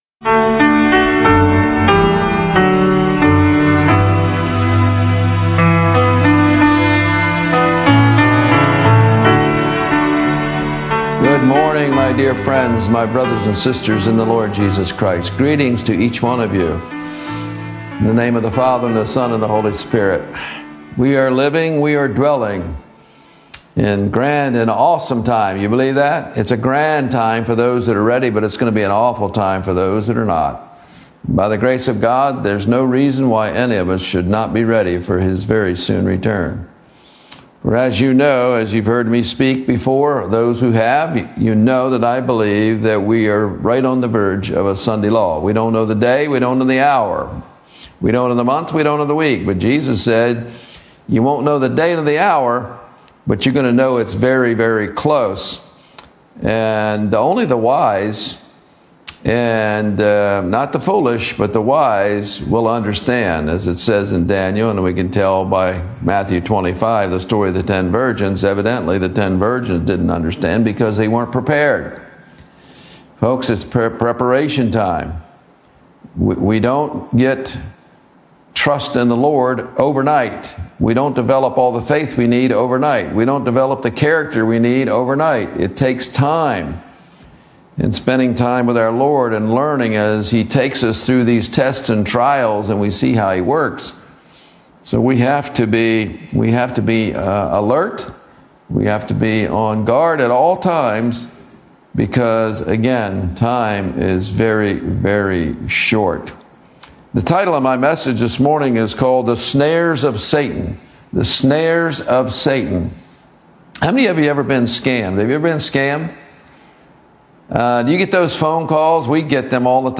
This powerful message exposes the escalating spiritual war against God’s remnant, revealing how deception, false teachings, and spiritualism threaten to infiltrate even the church itself. Grounded in Revelation and the Spirit of Prophecy, the sermon urges believers to stand firm in truth, embrace personal holiness, and engage in vigilant spiritual warfare through God’s Word and power.